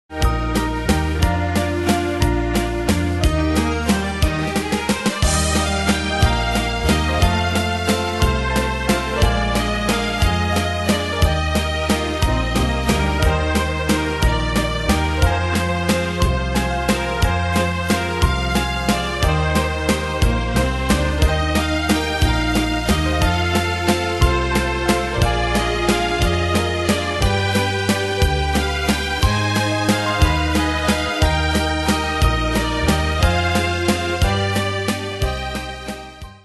Style: Retro Ane/Year: 1958 Tempo: 180 Durée/Time: 3.05
Danse/Dance: Valse/Waltz Cat Id.
Pro Backing Tracks